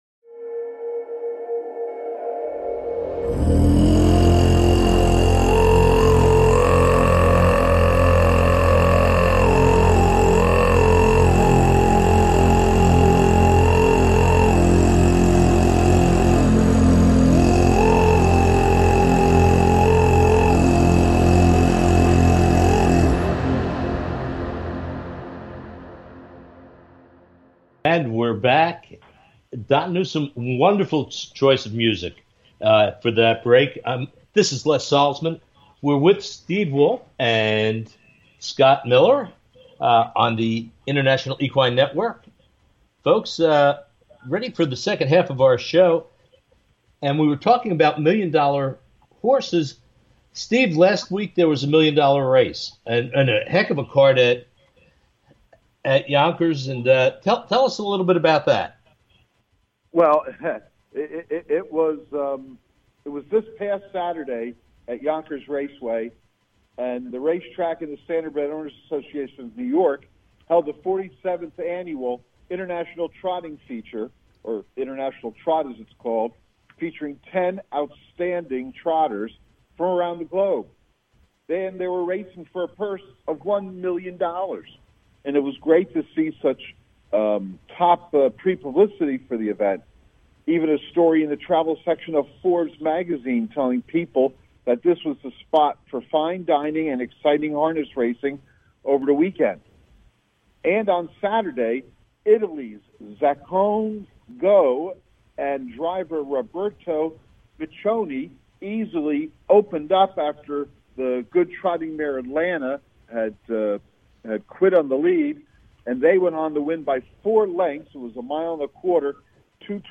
Talk Show
Calls-ins are encouraged!